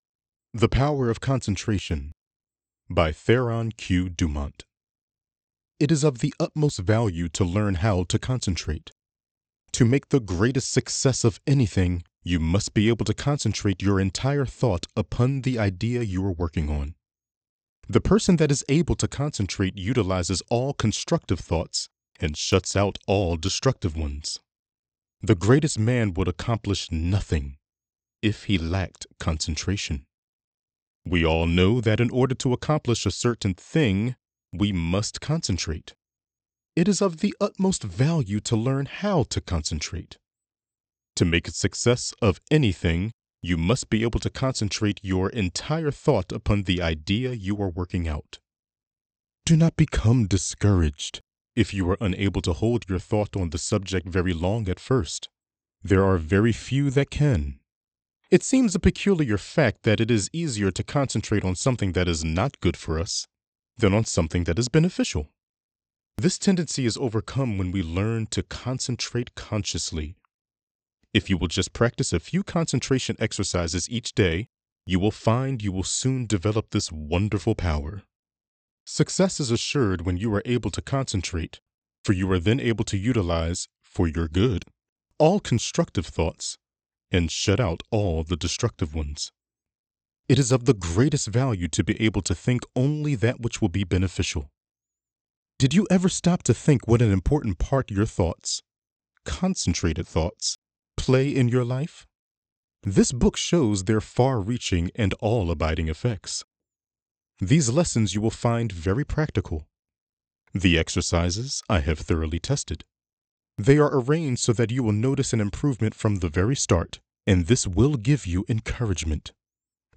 Audiobook Samples
Intellectual and Inspiring